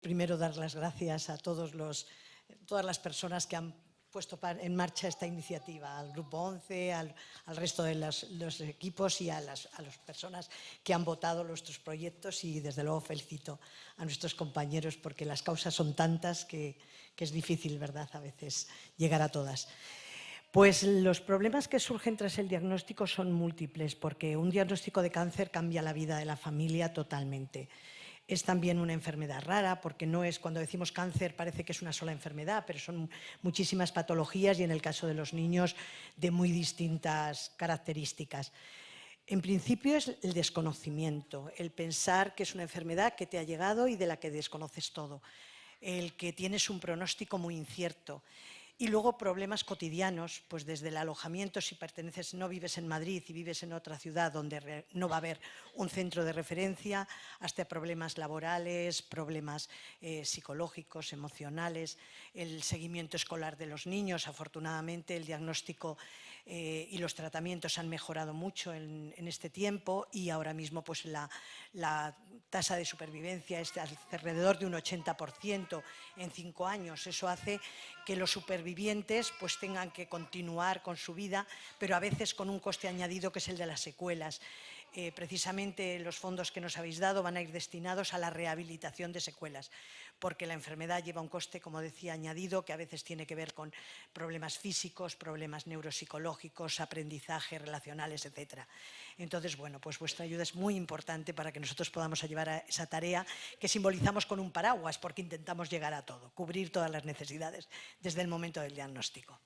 El acto de entrega de los cheques solidarios del programa ‘Gracias A Ti’ reunió a trabajadores de todas las áreas ejecutivas del Grupo Social ONCE (ONCE, Fundación ONCE e ILUNION) en un acto celebrado en el Palacete de los Duques de Pastrana en Madrid el pasado 10 de febrero, que también pudo seguirse en streaming.